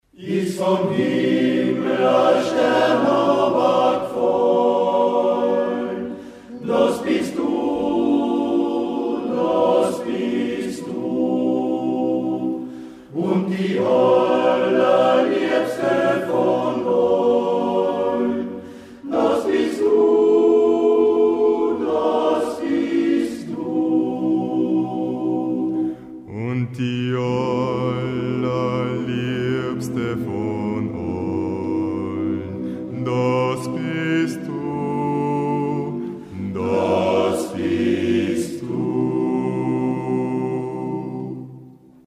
• Aufgenommen im März 2005 in der Volksschule Poggersdorf